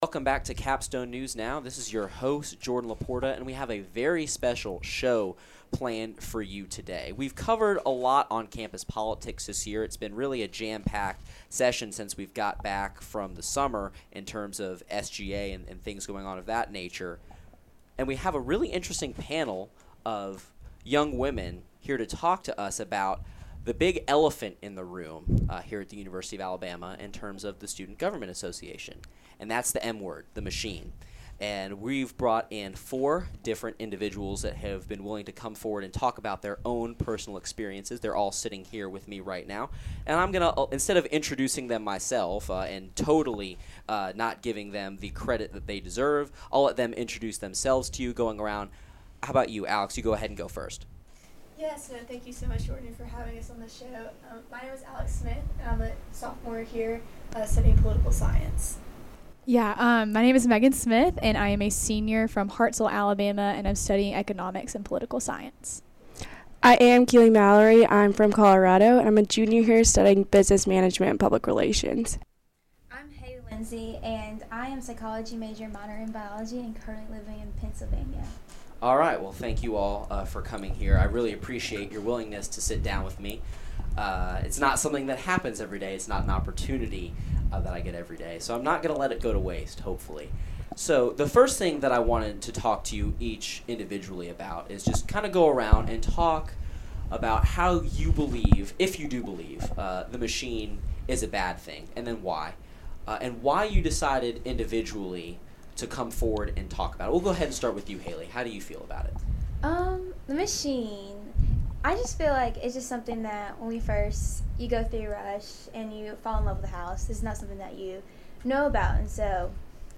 Exclusive: tell-all sit-down with sorority members from Machine houses